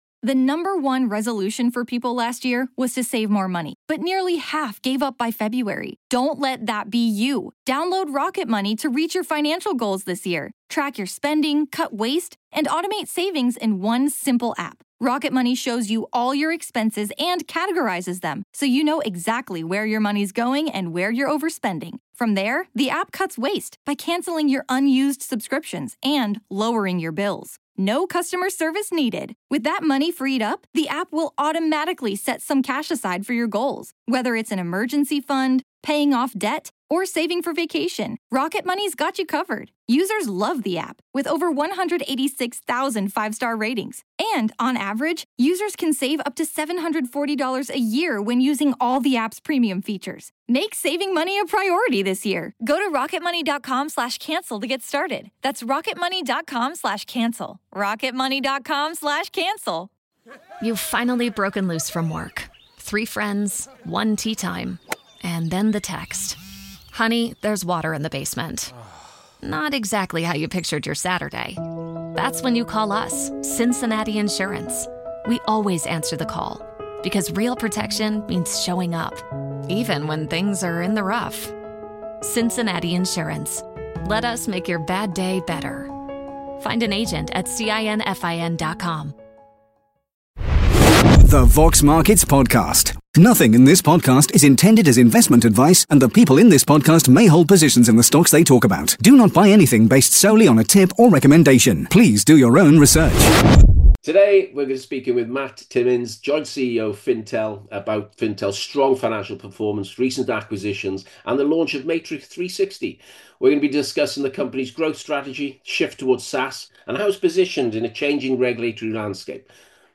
Fintel Interview